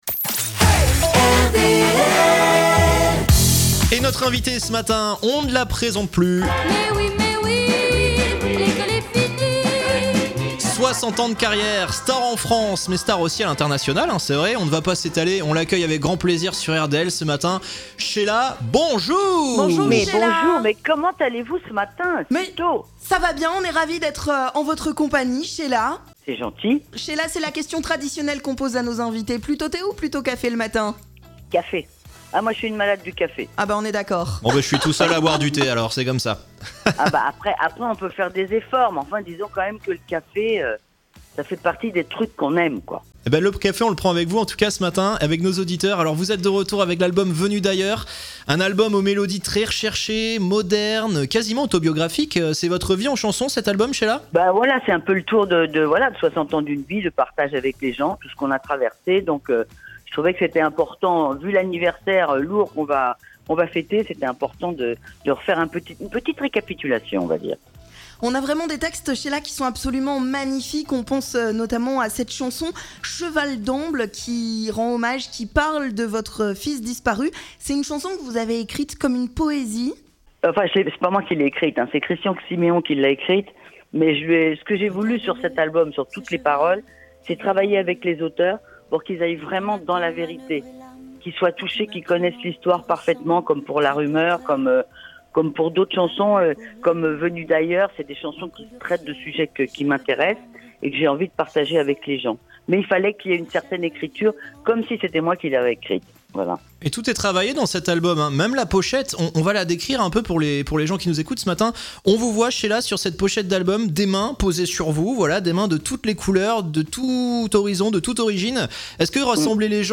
Pour la sortie de son dernier album "Venue d'ailleurs", réecoutez l'interview de Sheila sur RDL !